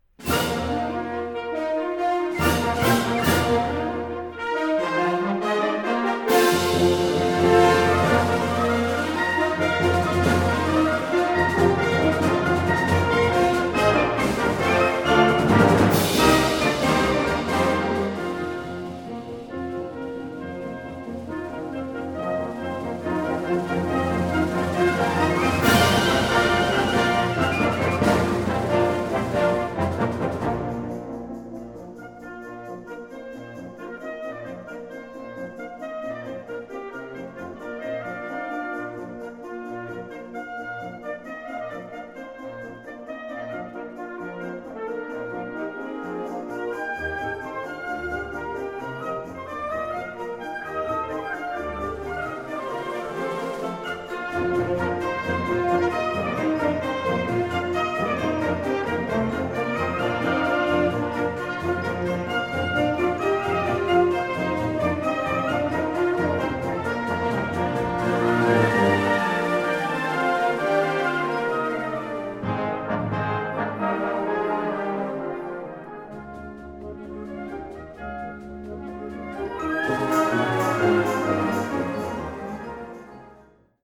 Kategorie Blasorchester/HaFaBra
Unterkategorie Ouvertüre (Originalkomposition)
Besetzung Ha (Blasorchester)